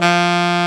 Index of /90_sSampleCDs/Roland L-CDX-03 Disk 1/SAX_Alto Short/SAX_A.mf 414 Sh
SAX A.MF F00.wav